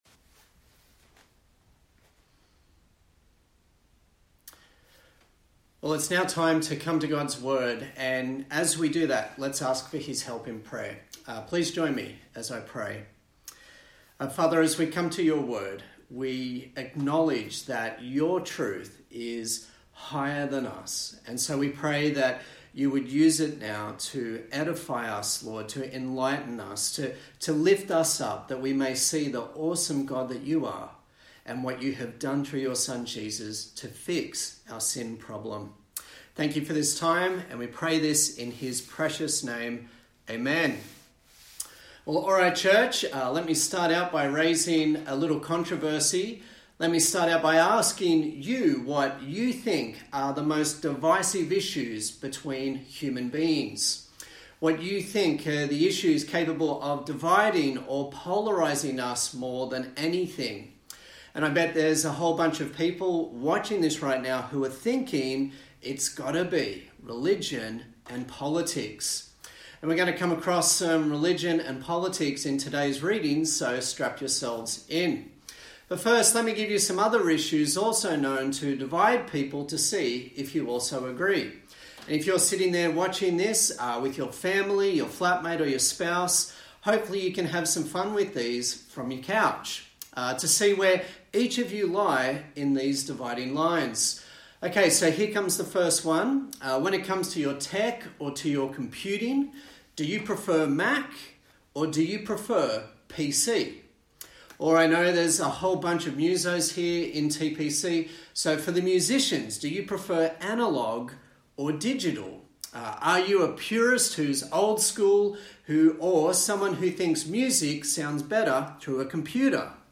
A sermon in the series on the book of Acts
Acts Passage: Acts 13:42-14:7 Service Type: Sunday Morning